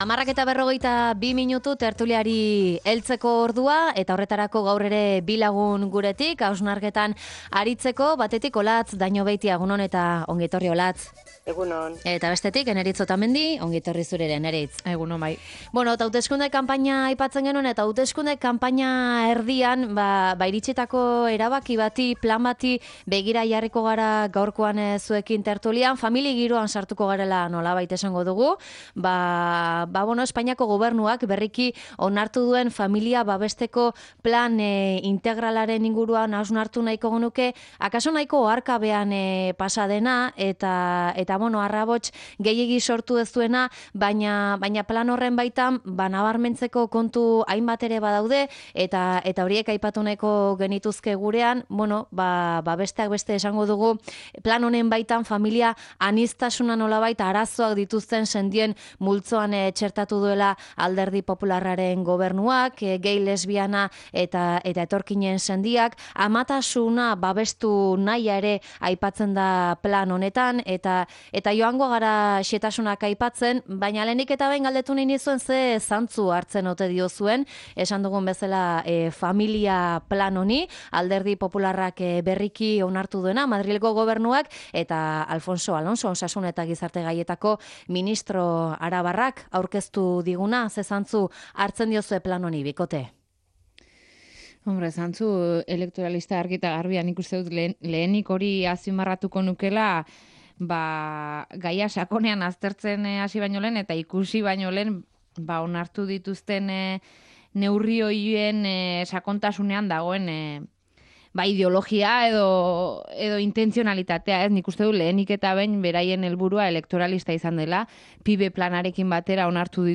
Tertulia: Familia anitzak, nuklearra…zein familia babesteko plana?